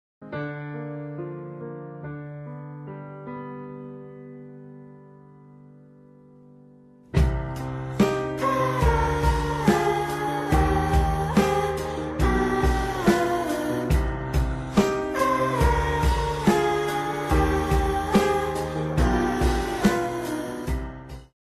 Звук горького разочарования в меме, когда реальность не оправдала ожиданий